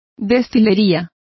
Complete with pronunciation of the translation of distilleries.